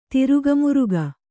♪ tiruga muruga